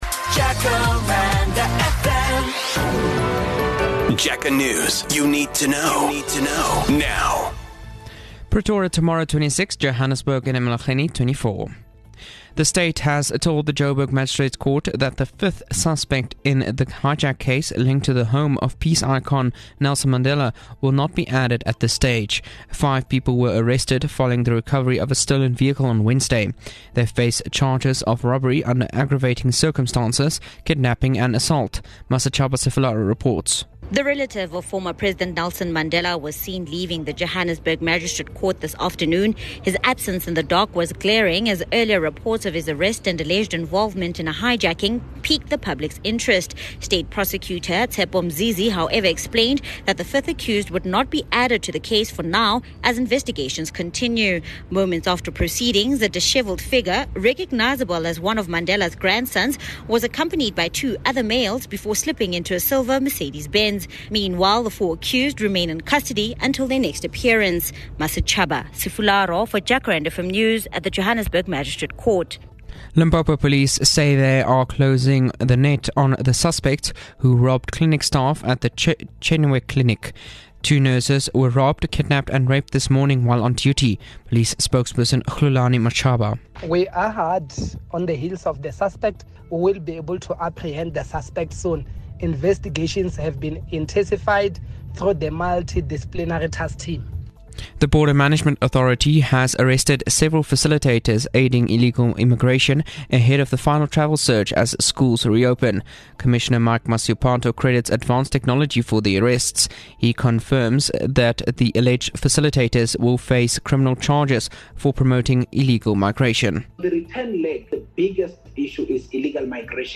Jacaranda FM News Bulletins